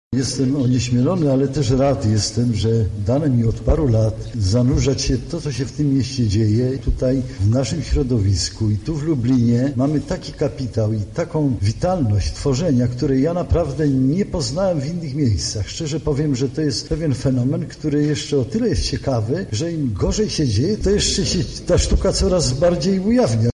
— mówi  Leszek Mądzik, przewodniczący komisji konkursowej